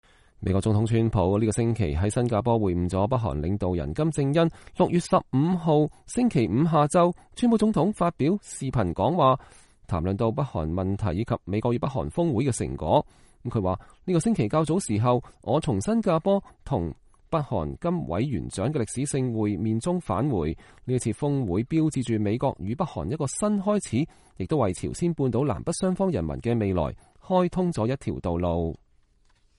川普總統有關北韓問題的視頻講話